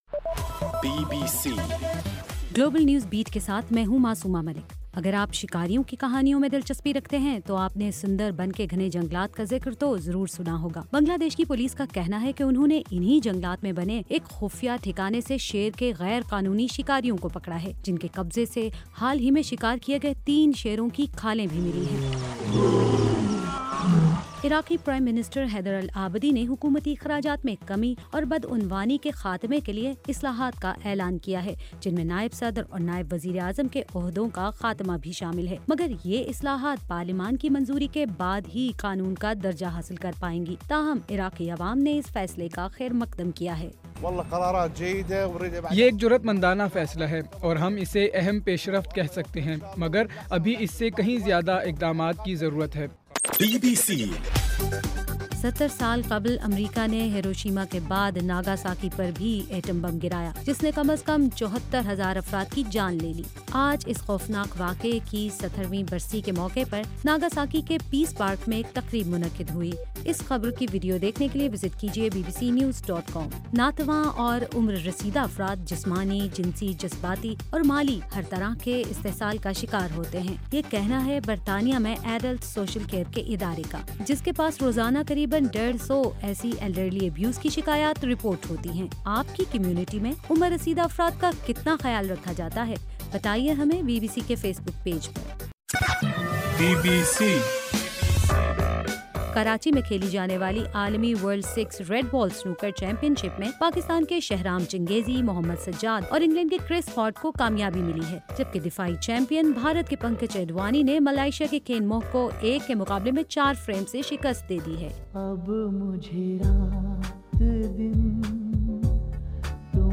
اگست 9: رات 11 بجے کا گلوبل نیوز بیٹ بُلیٹن